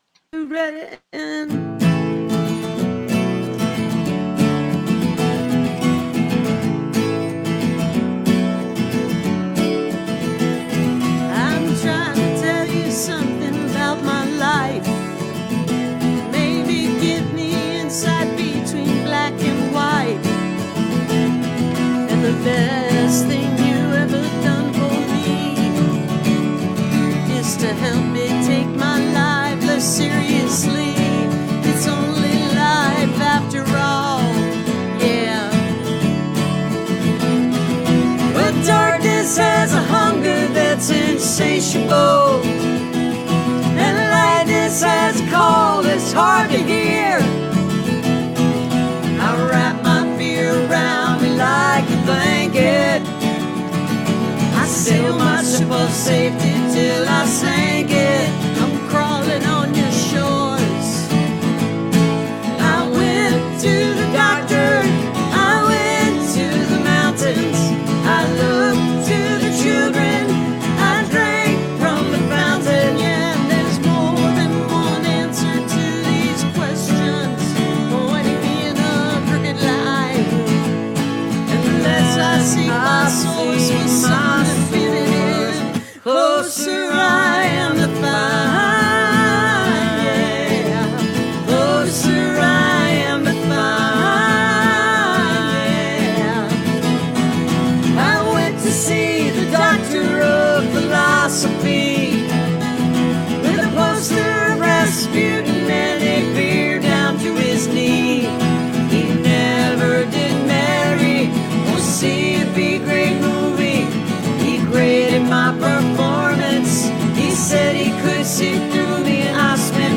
(captured from facebook)